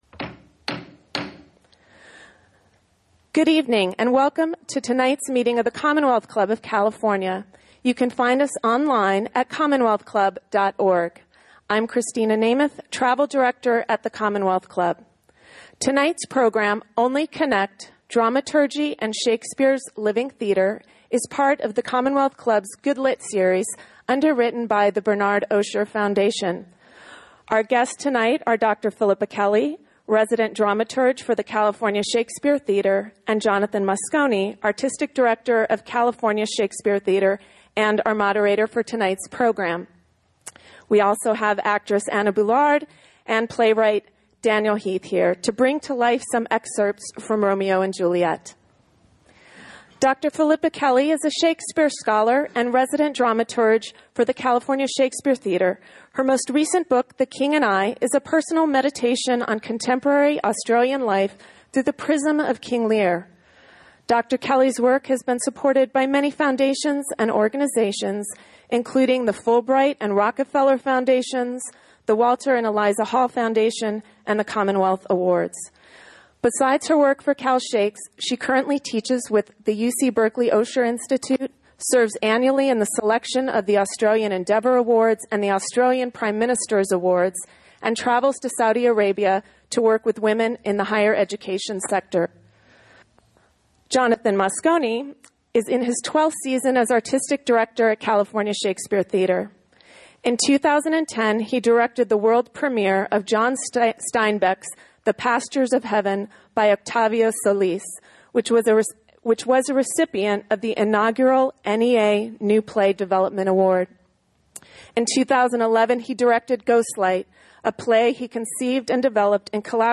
Excerpts from Romeo and Juliet will be performed during the session by actress